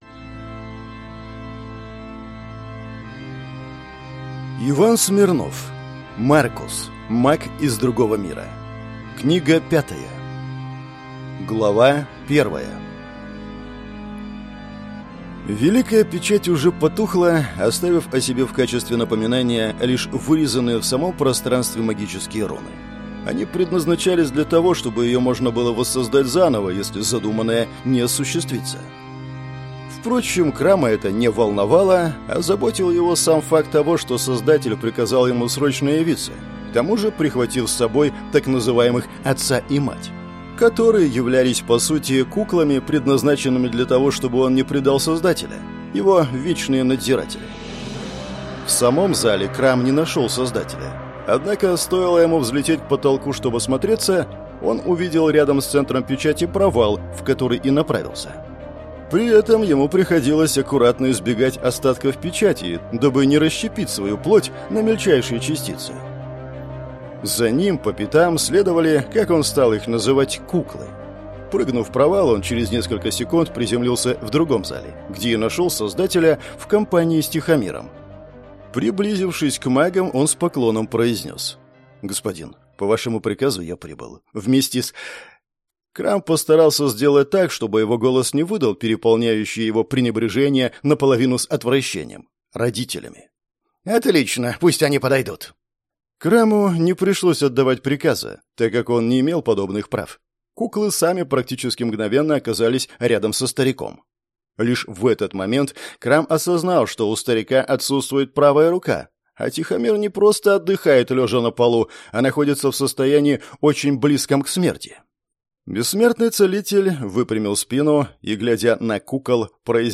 Аудиокнига Маркус. Маг из другого мира. Книга 5 | Библиотека аудиокниг